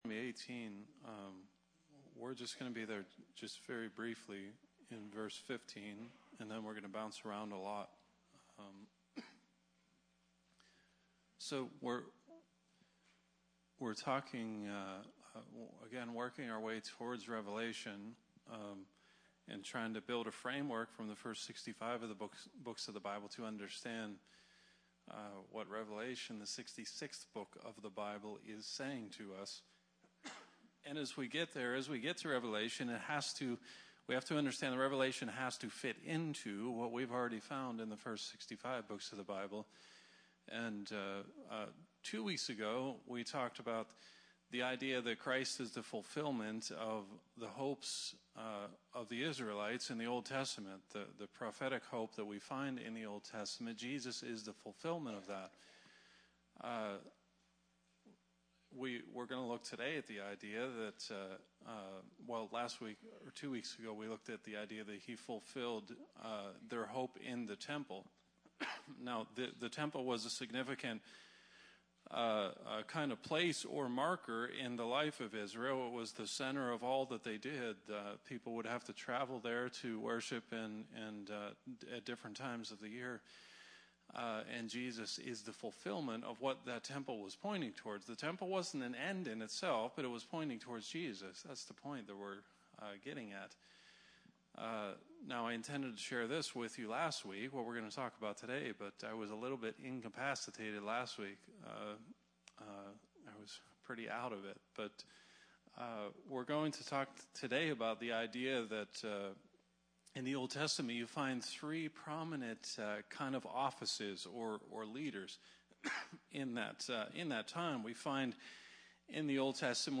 Sermons | Barrs Mill Church of God